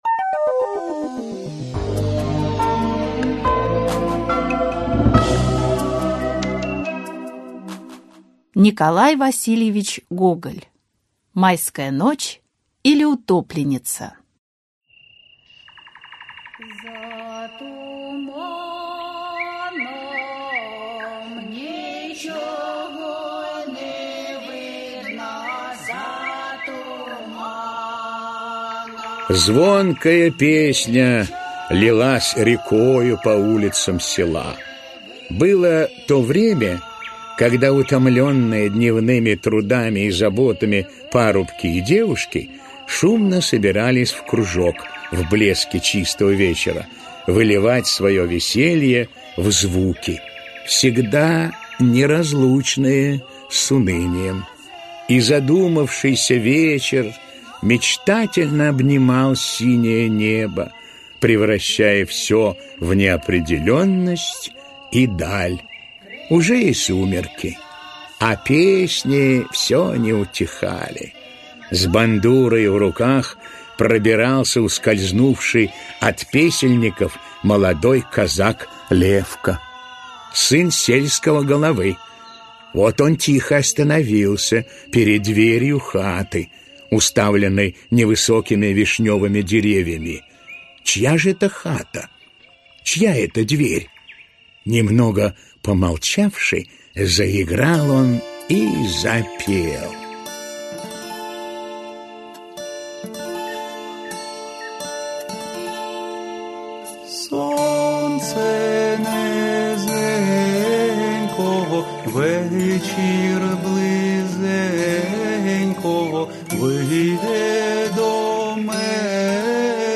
Аудиокнига Майская ночь, или Утопленница. Аудиоспектакль | Библиотека аудиокниг